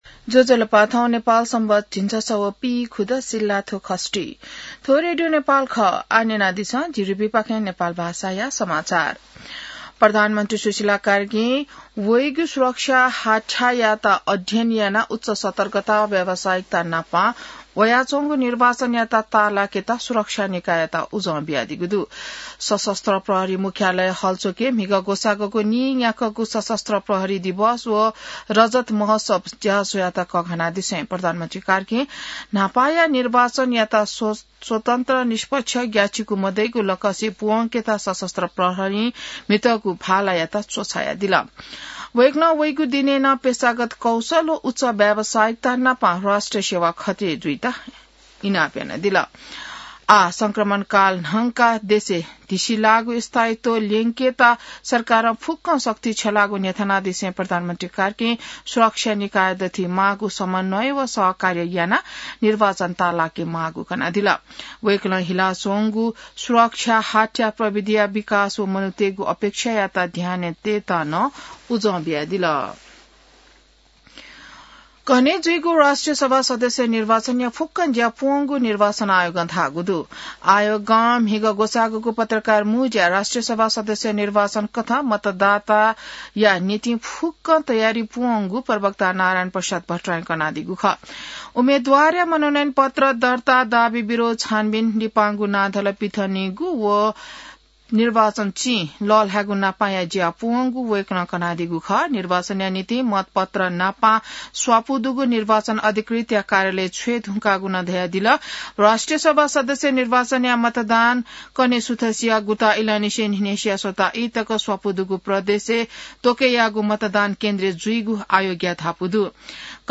नेपाल भाषामा समाचार : १० माघ , २०८२